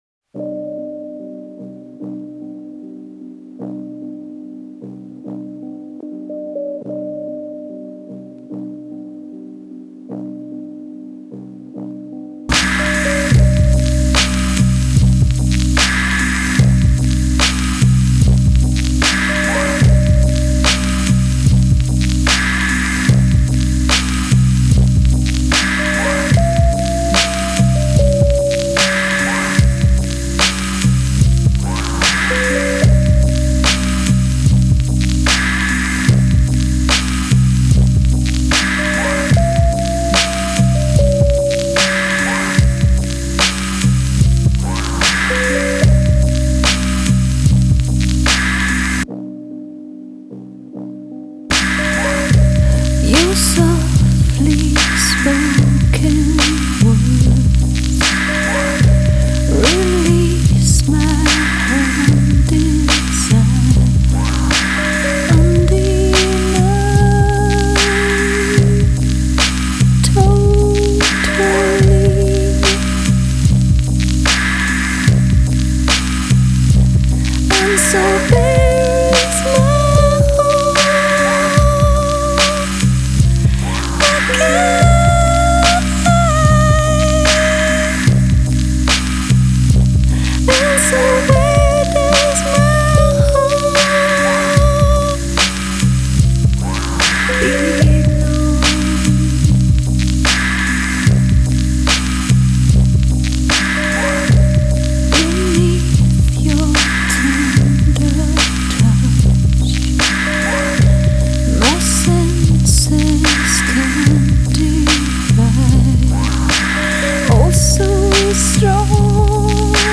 Trip Hop